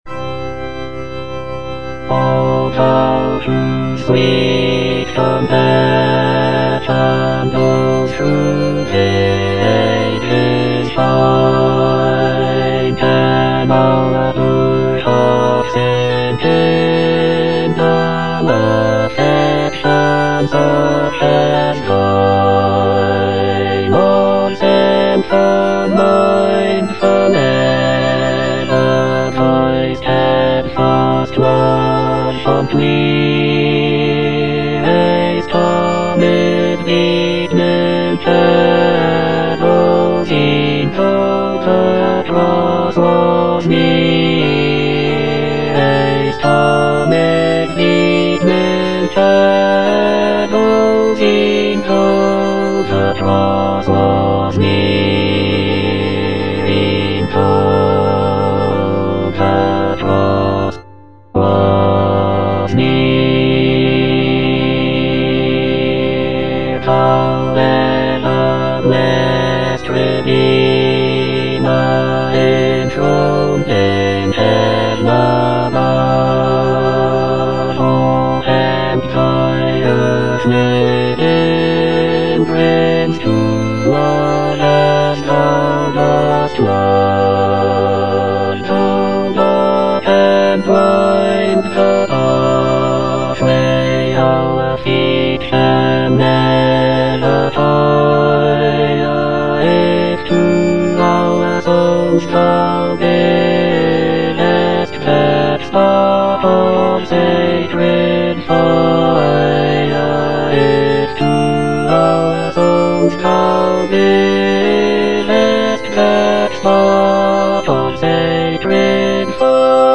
J.H. MAUNDER - OLIVET TO CALVARY 5b. O Thou Whose sweet compassion - Bass (Emphasised voice and other voices) Ads stop: auto-stop
"Olivet to Calvary" is a sacred cantata composed by John Henry Maunder in 1904.